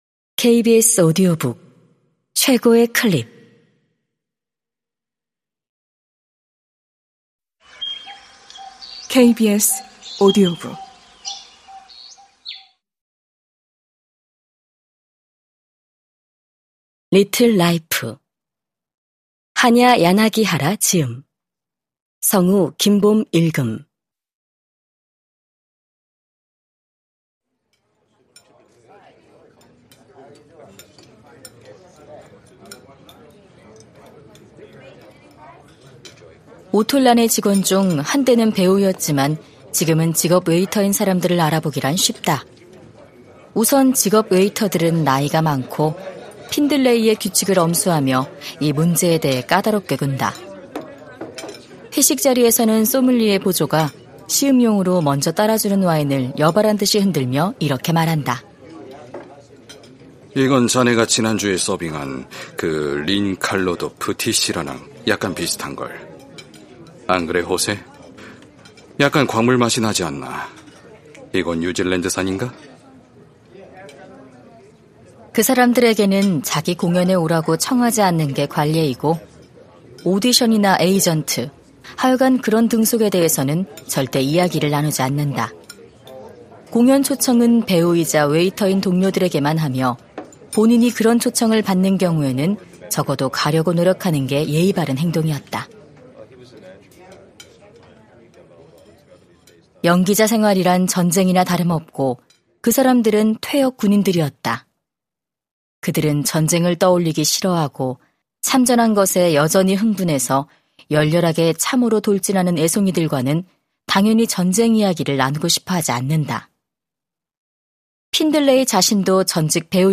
KBS오디오북_리틀 라이프_한야 야나기하라_성우